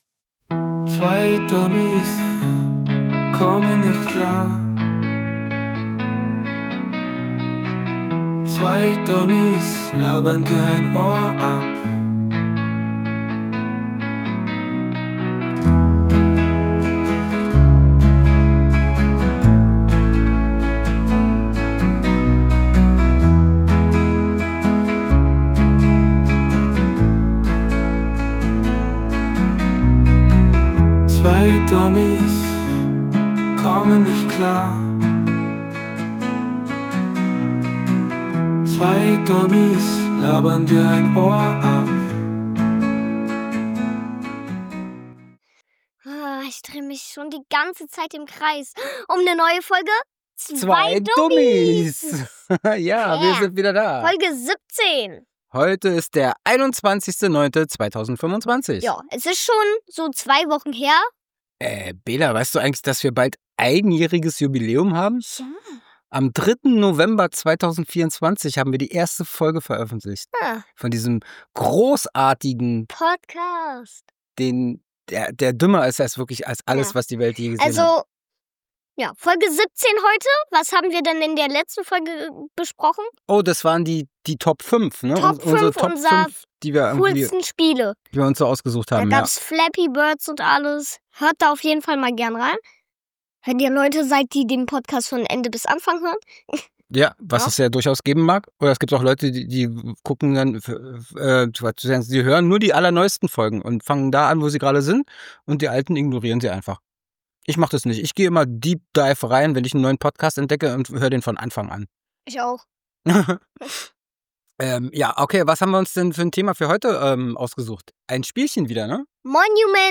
Beschreibung vor 6 Monaten Dieser Podcast vom 21.9.2025 beginnt mit dem für das Format typischen, selbstironischen Geplapper der Hosts und der Ankündigung des aktuellen Themas: dem Puzzle-Adventure-Spiel Monument Valley. Die Diskussion konzentriert sich auf die einzigartigen visuellen und rätselhaften Elemente des Spiels, die stark von den unmöglichen Architekturen des Künstlers M. C. Escher inspiriert sind, bei denen optische Täuschungen genutzt werden, um Prinzessin Ida durch die Levels zu führen.